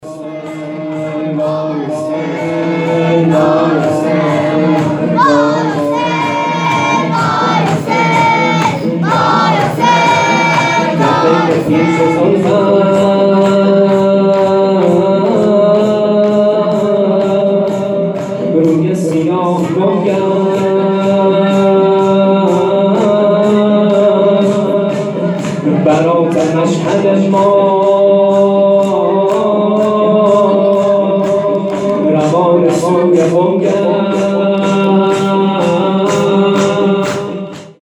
خیمه گاه - شجره طیبه صالحین - در بین پیچ زلفت _ شور نغمه خوانی